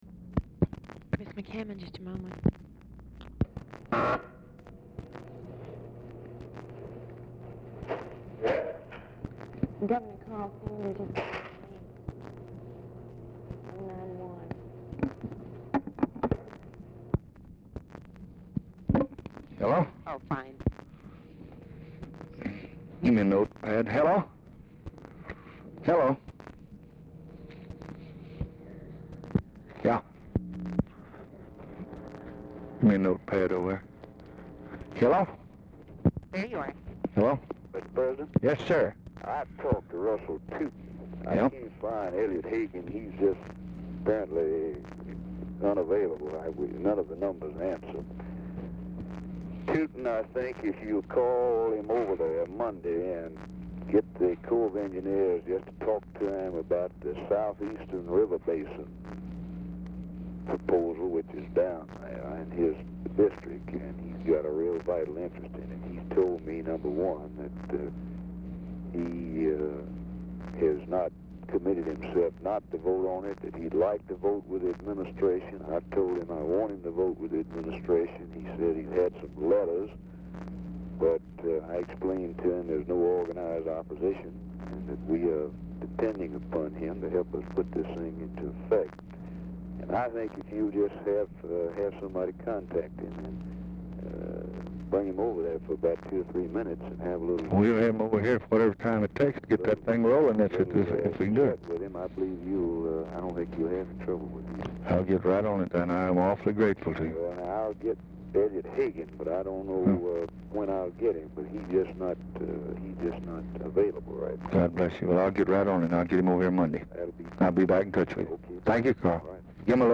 DICTABELT PREVIOUSLY MISFILED; SANDERS ON HOLD 0:37; OFFICE CONVERSATION PRECEDES CALL
Format Dictation belt
Specific Item Type Telephone conversation